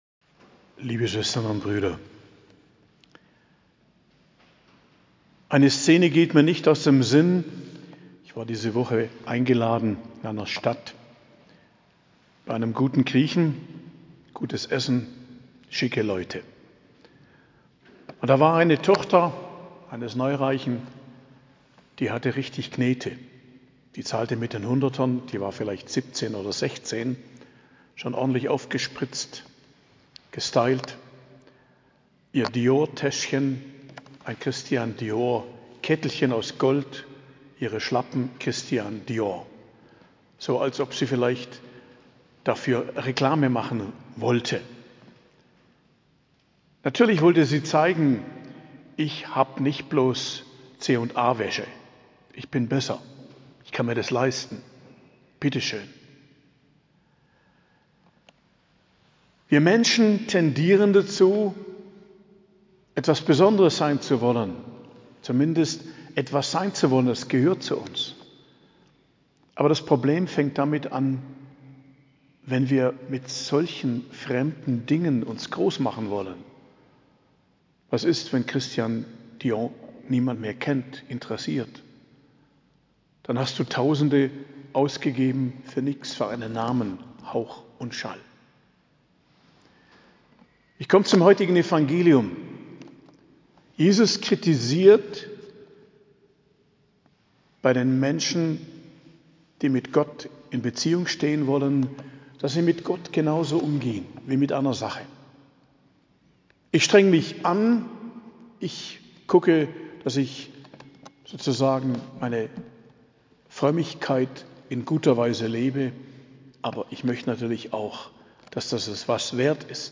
Predigt am Mittwoch der 11. Woche i.J., 21.06.2023